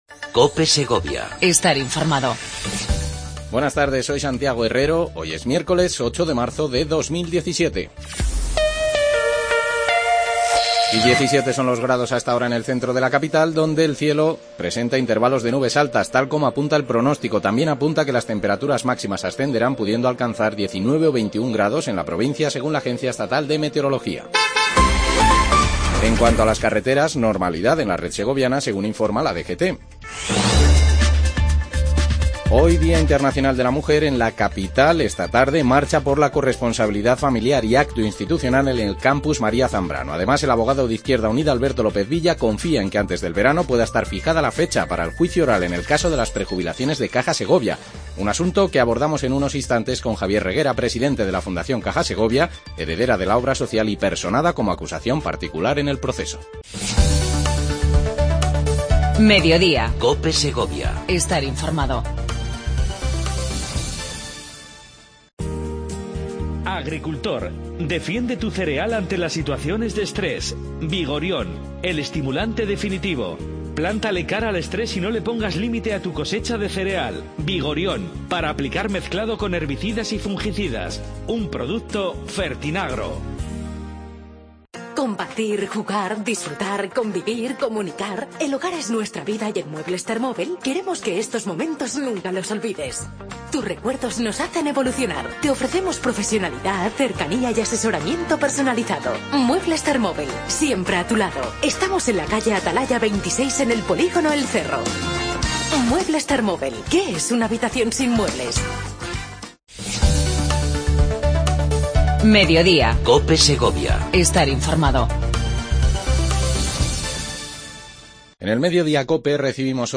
AUDIO: Avance de las noticias más destacadas del día. Entrevista